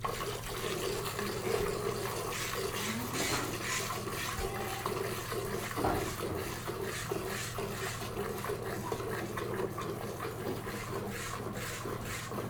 milk.wav